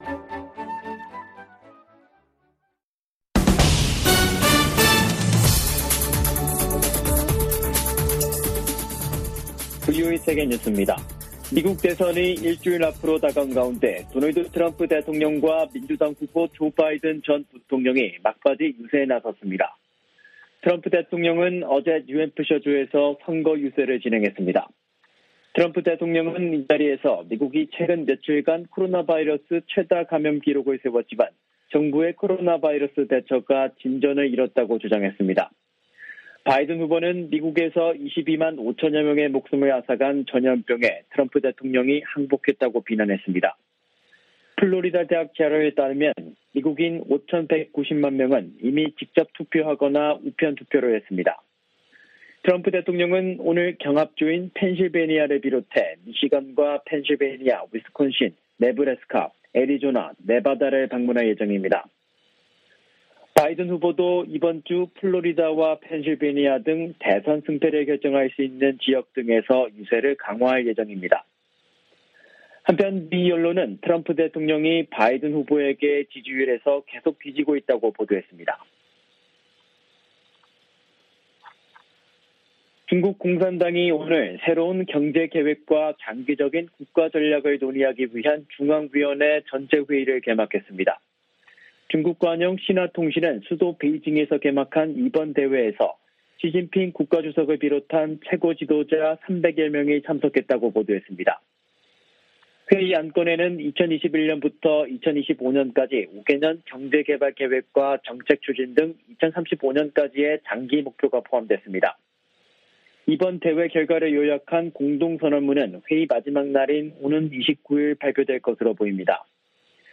VOA 한국어 간판 뉴스 프로그램 '뉴스 투데이', 3부 방송입니다.